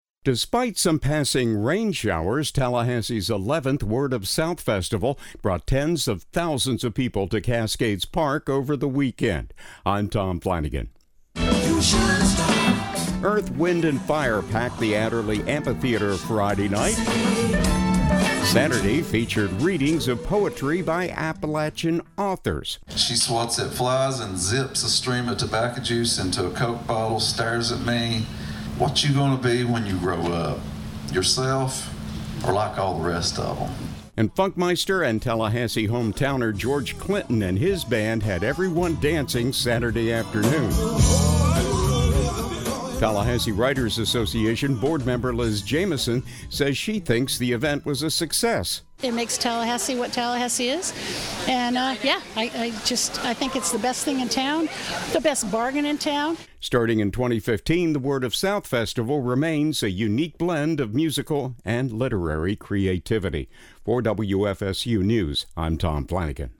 Despite some passing rain showers on Saturday, Tallahassee’s 11th Word of South Festival brought tens of thousands of people to Cascades Park over the weekend.
Among Saturday’s offerings was readings of poetry by Appalachian authors.